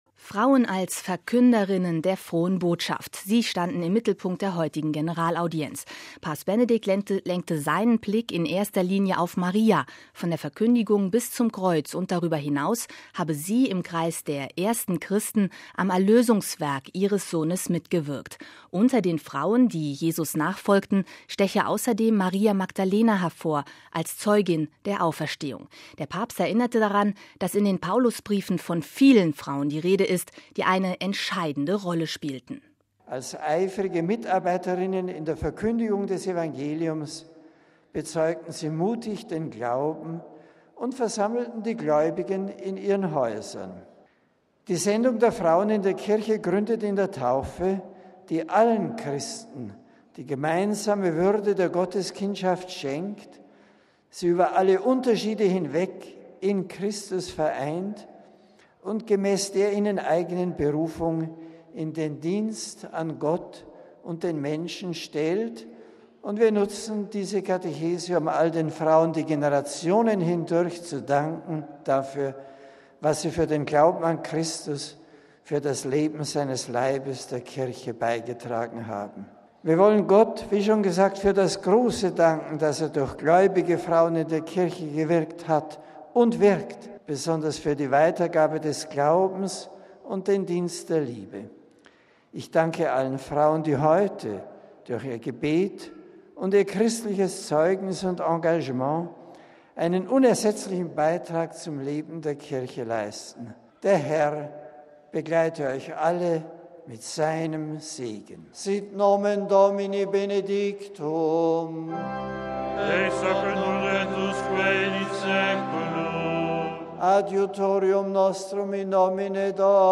MP3 Frauen als "Verkünderinnen“ der Frohen Botschaft – sie standen im Mittelpunkt der heutigen Generalaudienz. Papst Benedikt lenkte seinen Blick in erster Linie auf Maria.
Er grüßte die deutschen Pilger mit einem Loblied an das weibliche Geschlecht: